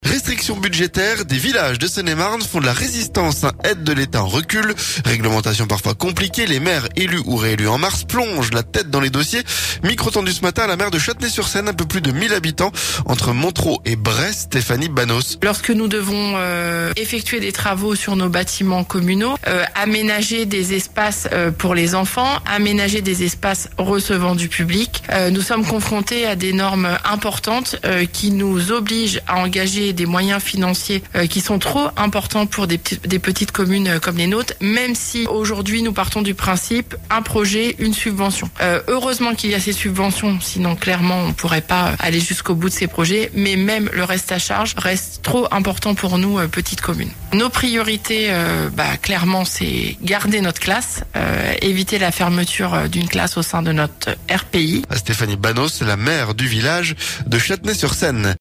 Micro tendu ce mercredi à la maire de Châtenay-sur-Seine, un peu plus de 1.000 habitants entre Montereau et Bray-sur-seine, Stéphanie Banos.